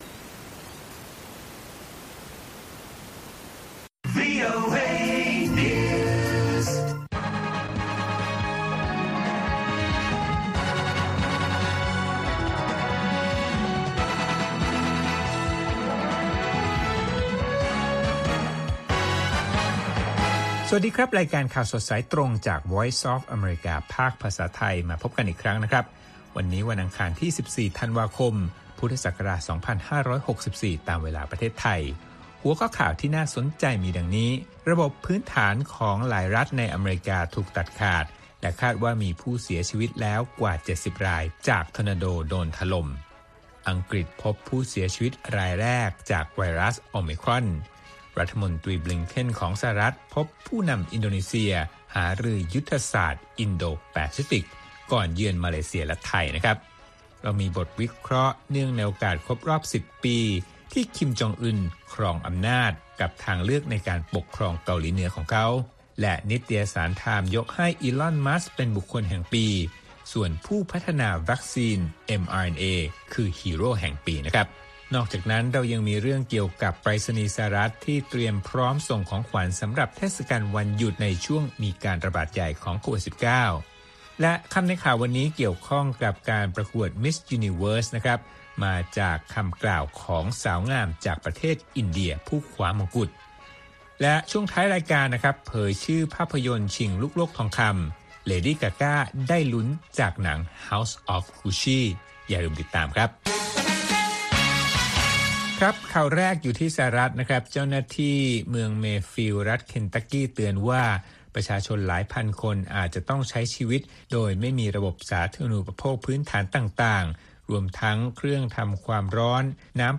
ข่าวสดสายตรงจากวีโอเอ ภาคภาษาไทย ประจำวันอังคารที่ 14 ธันวาคม 2564 ตามเวลาประเทศไทย